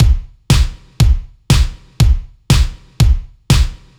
Index of /musicradar/french-house-chillout-samples/120bpm/Beats
FHC_BeatC_120-03_KickSnare.wav